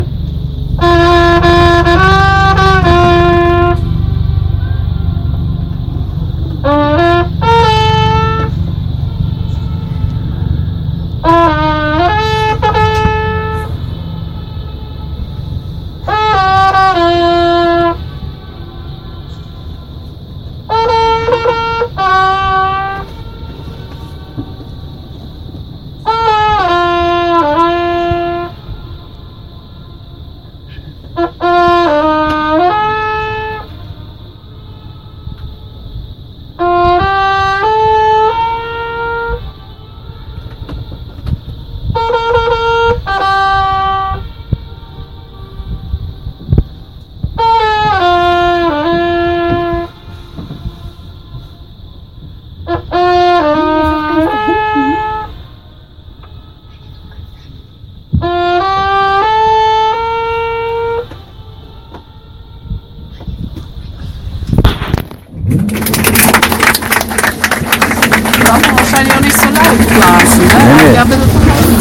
seinen Waldhorn das mehrfache Echo =>
Koenigsee-echo.mp3